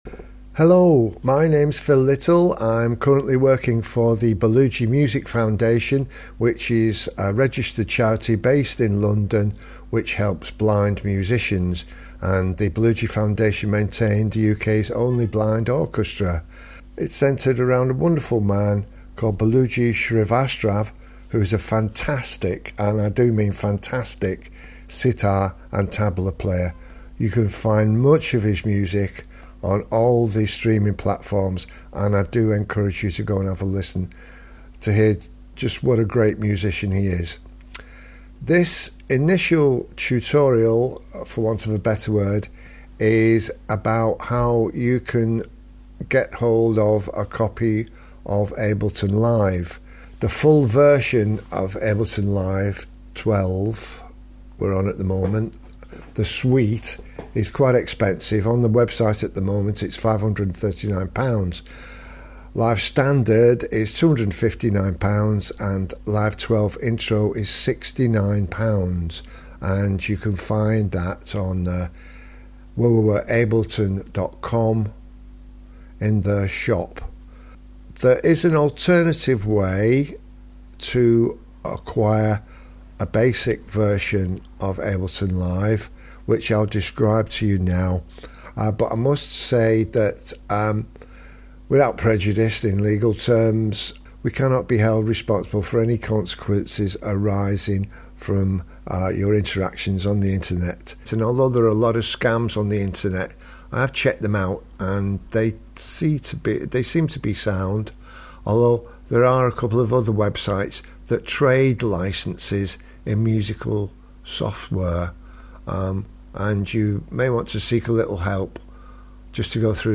This webpage is intended to provide assistance to blind and partially sighted people wishing to compose and record their own music in Ableton Live. The content takes the form of Tutorials recorded as audio files.